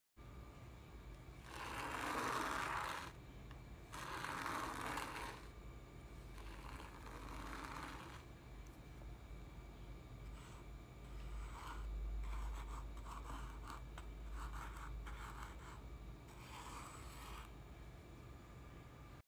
Sand writing name ASMR | Relaxing sounds